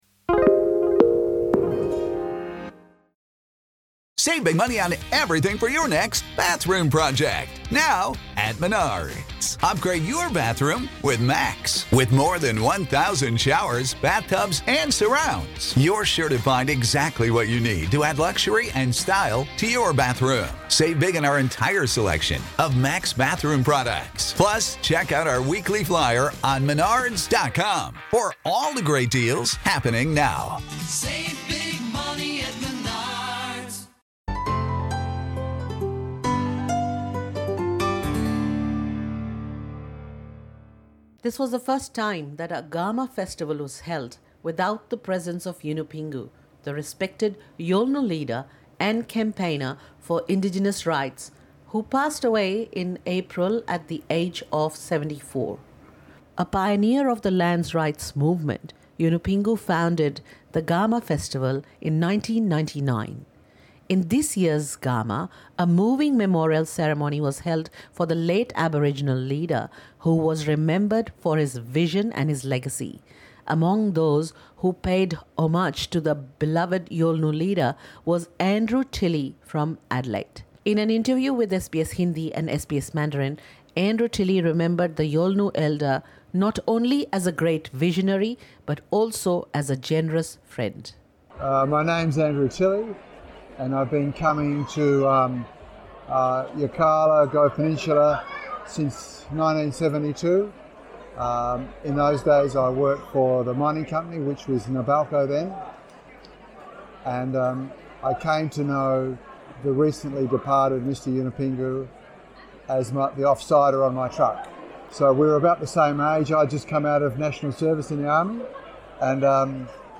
In an interview with SBS Hindi and SBS Mandarin